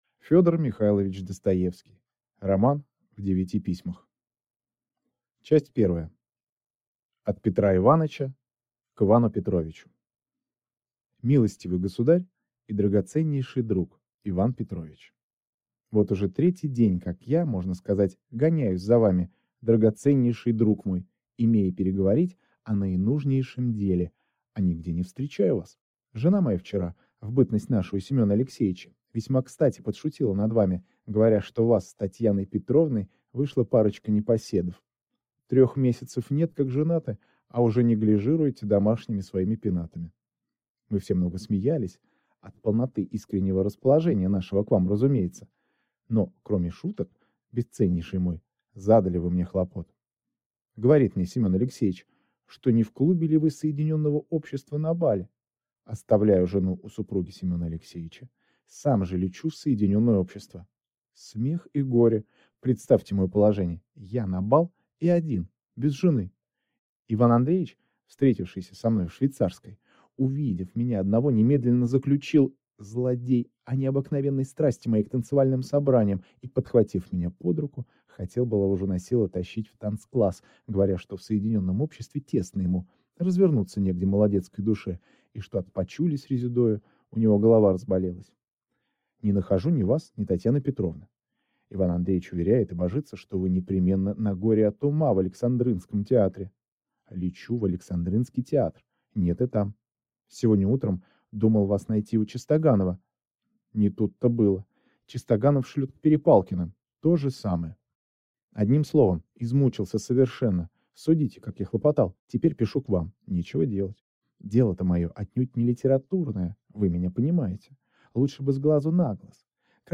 Аудиокнига Роман в девяти письмах | Библиотека аудиокниг